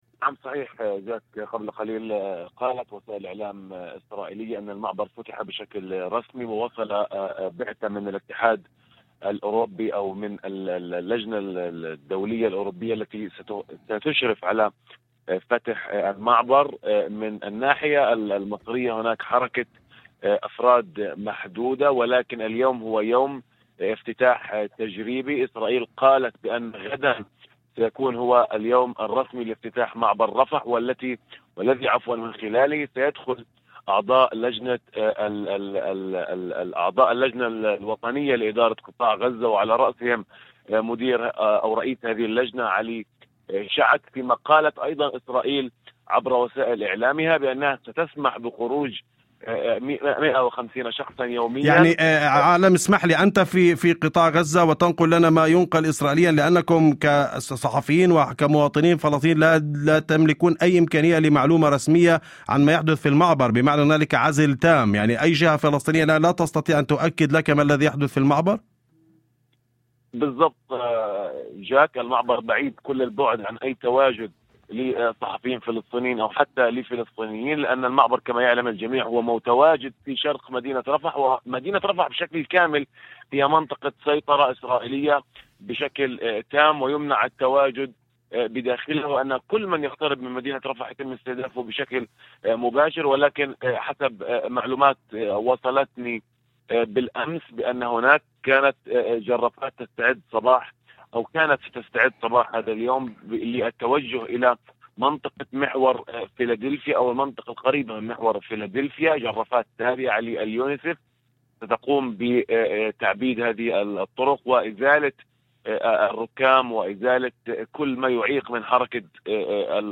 وفي مداخلة هاتفية لبرنامج "أول خبر" على إذاعة الشمس